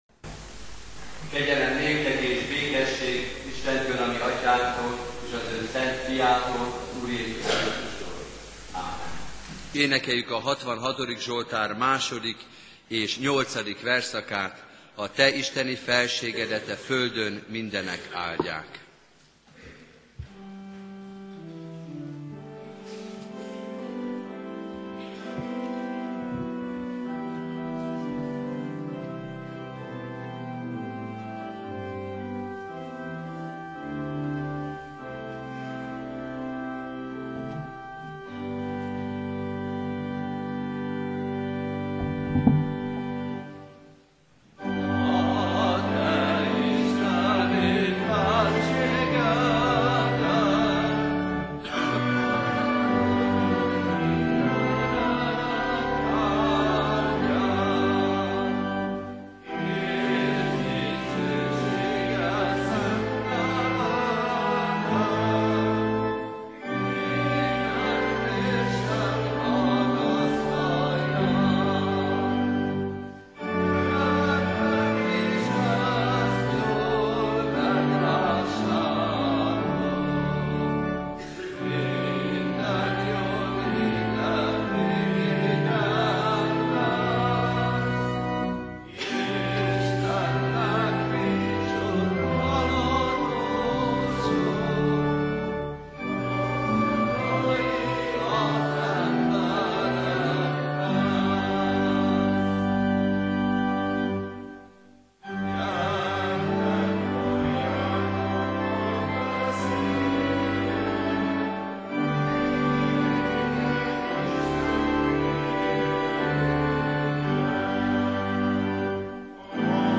Igehirdetések Hazaszeretetről